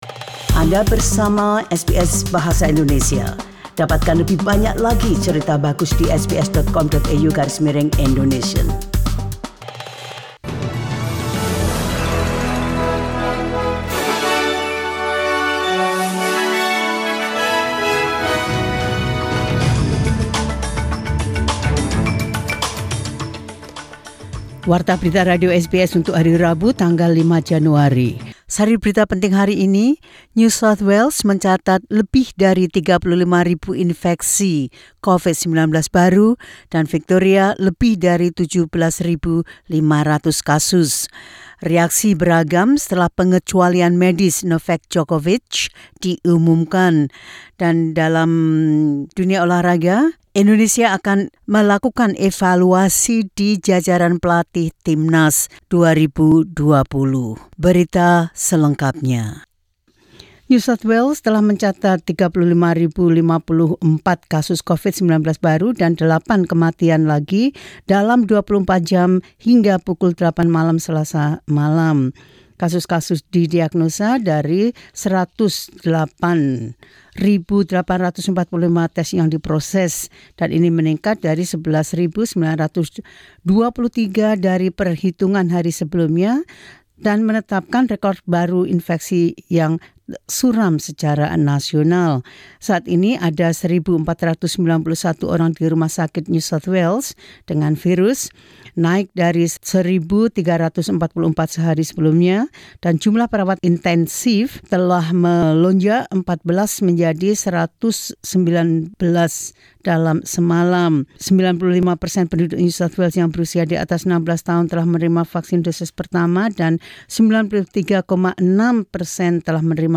Warta Berita Radio SBS Program Bahasa Indonesia – 05 Jan 2022